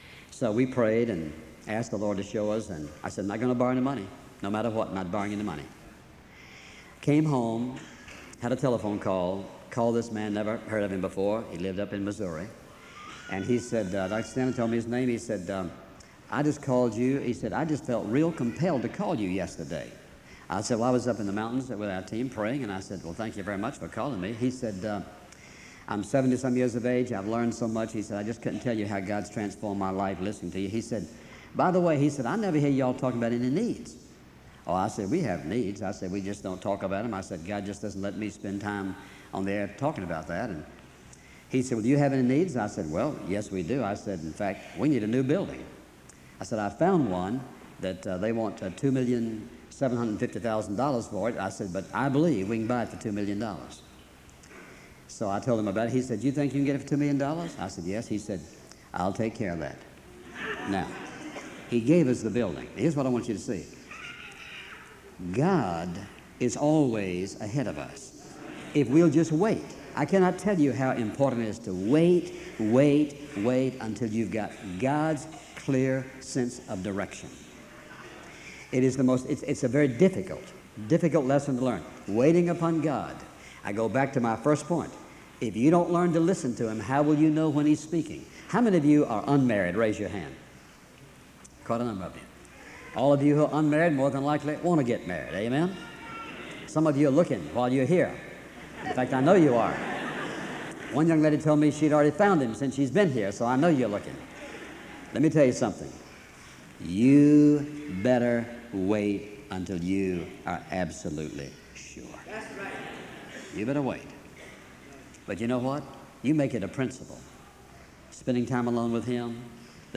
File Set | SEBTS_Chapel_Charles_F_Stanley_1998-03-31_B.wav | ID: 905fdc9f-88e4-4f33-aa81-74c0bafa8ed7 | Hyrax